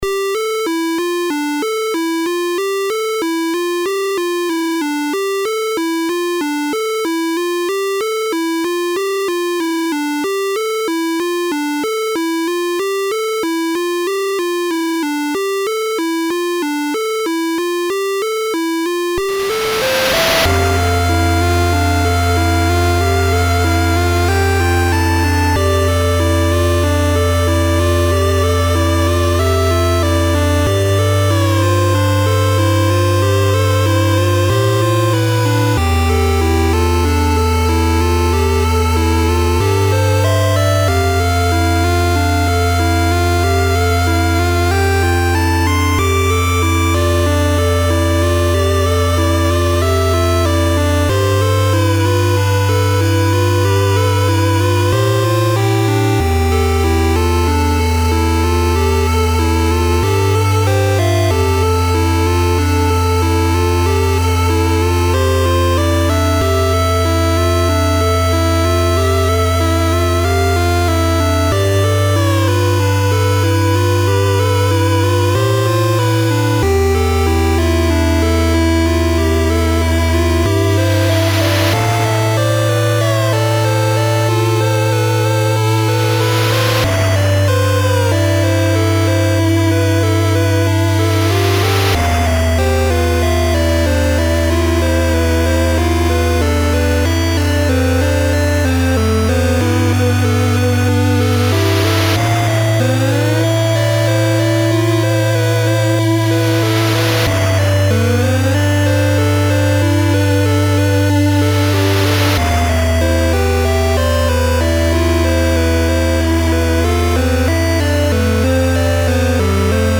Been making some chiptune as part of a art project. It's pretty much straight out of the Game Boy, aside from some reverb and compression, and I'm looking for a bit of feedback because I think it could use a bit more tweaking.